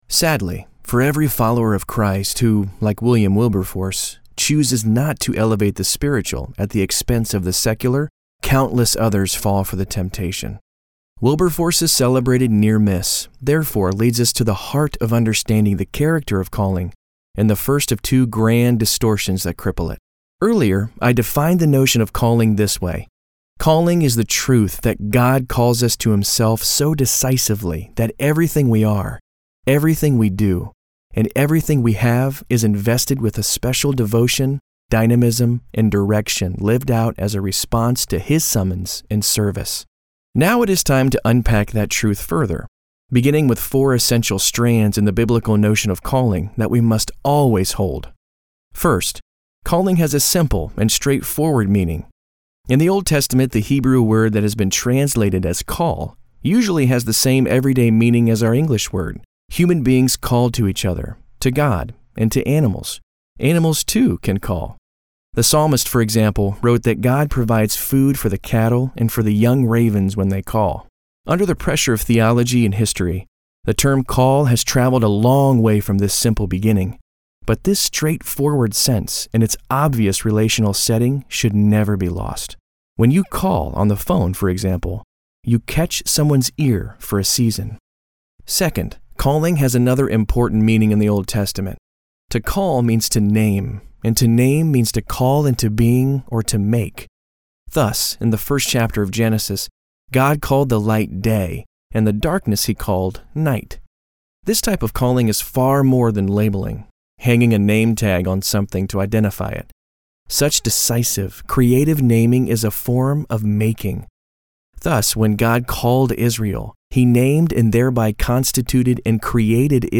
Male
I have a conversational, natural and Everyman type of voice.
Audiobooks
Words that describe my voice are Conversational, Natural, Everyman.
0222Audiobook_demo.mp3